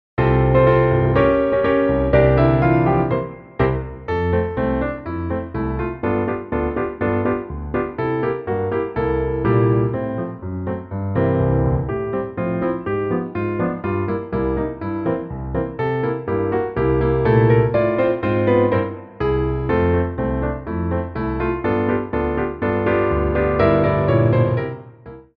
Coda
2/4 (8x8)